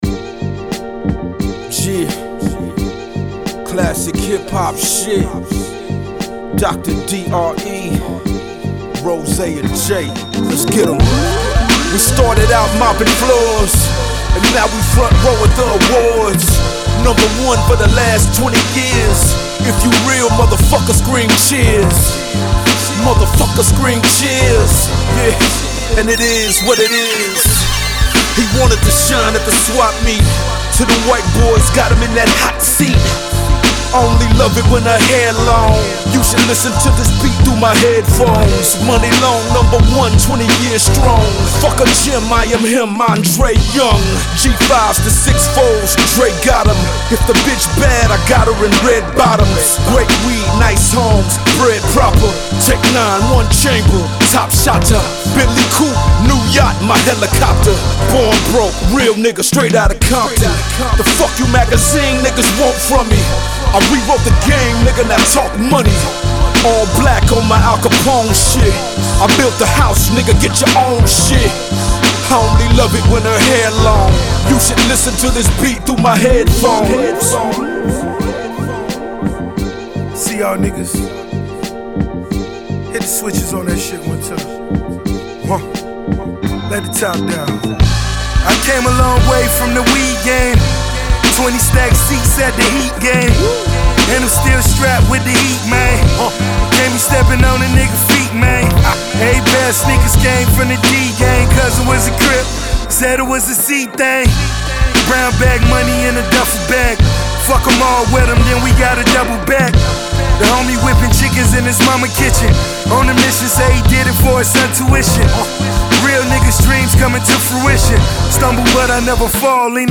WHO KNEW TJ MAXX WAS INTO HIP HOP